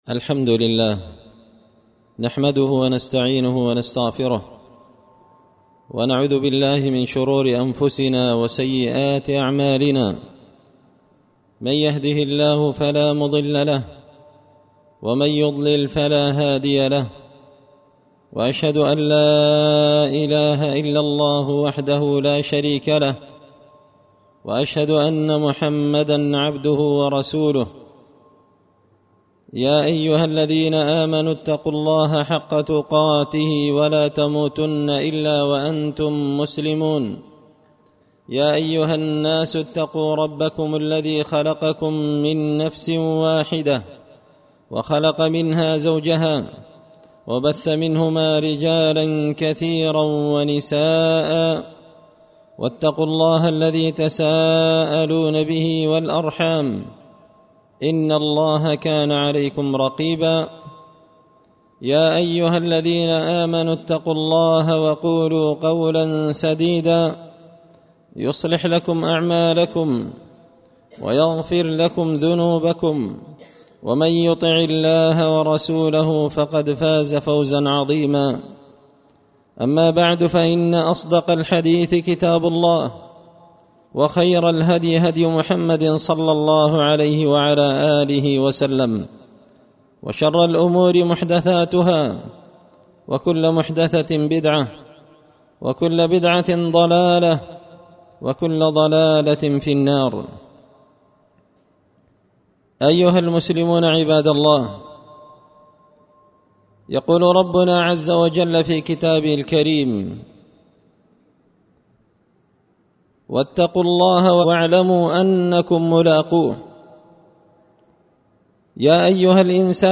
خطبة جمعة بعنوان – الذين لاينظر الله إليهم يوم القيامة
دار الحديث بمسجد الفرقان ـ قشن ـ المهرة ـ اليمن